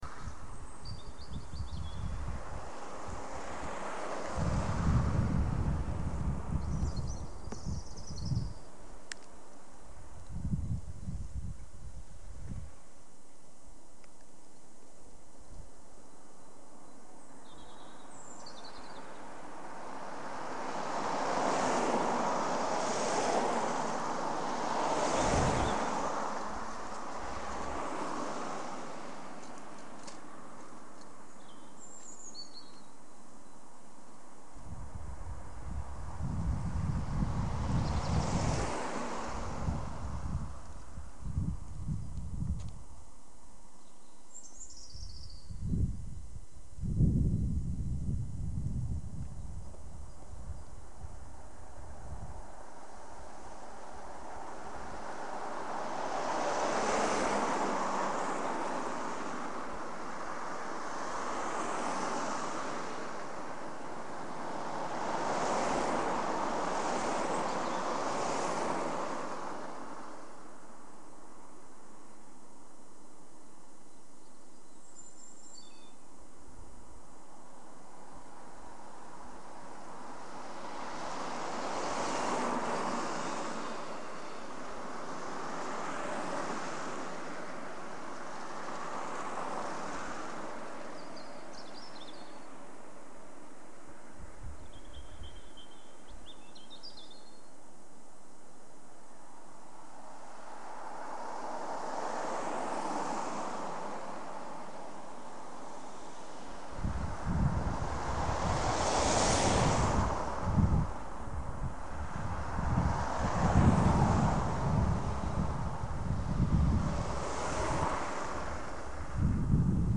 Bus stop Birds in afternoon 2 March 2013 (stereo soundscape)